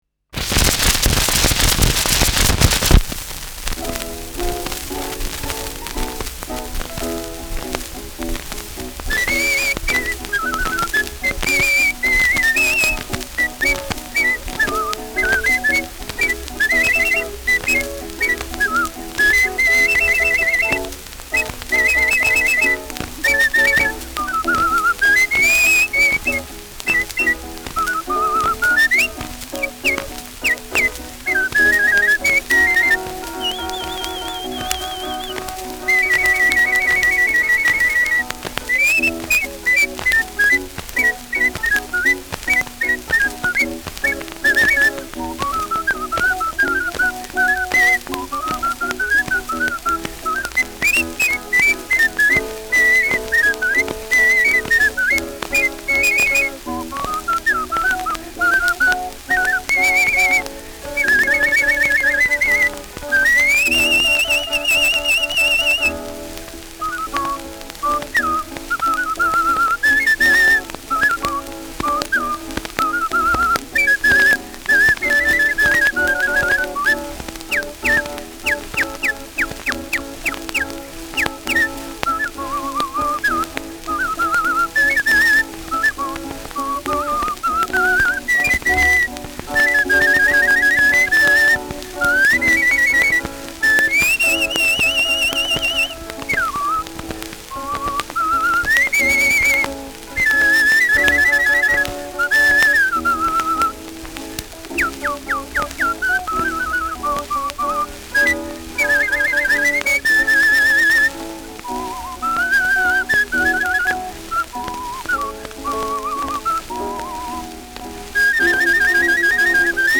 Schellackplatte
Abgespielt : Erhöhtes Grundrauschen : Durchgehend leichtes Knacken : Teils Klirren
mit eigener Zitherbegleitung